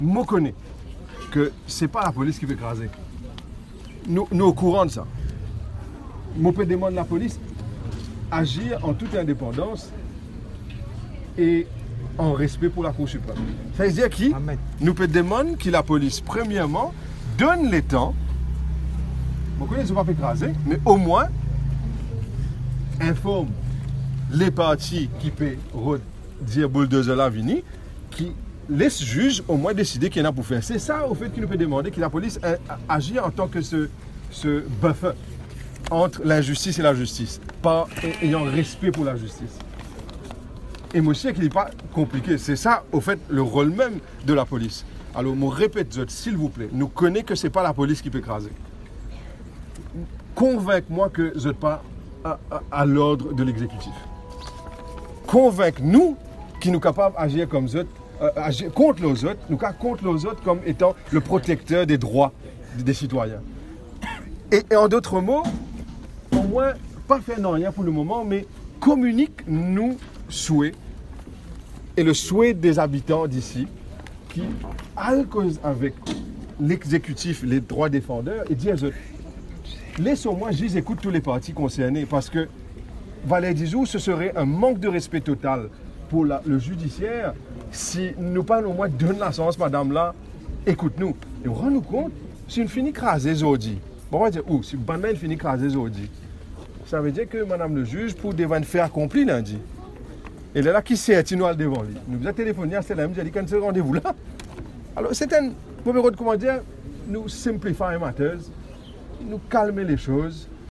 La Cour suprême doit être respectée, affirme Shakeel Mohamed. Le chef de file du Parti travailliste a débarqué à La Butte et a tenté de parlementer avec les autorités. La police doit agir comme un «buffer» entre la justice et l’injustice, souligne-t-il avec force.